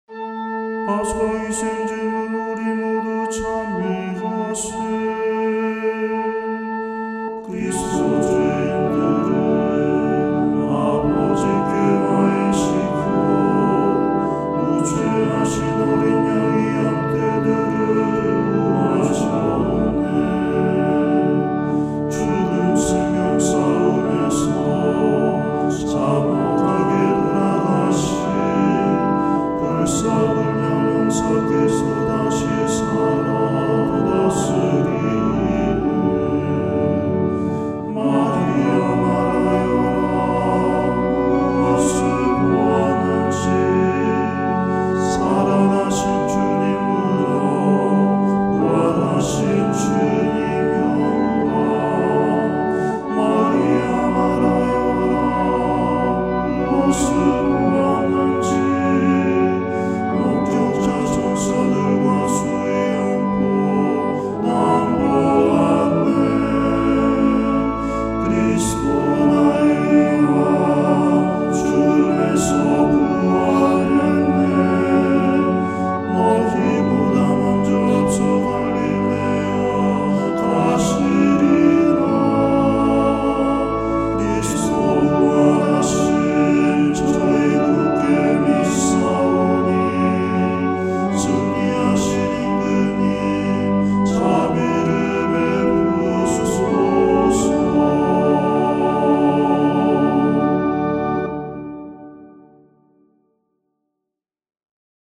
파스카 희생제물(Victimae Paschali) 부활 대축일 부속가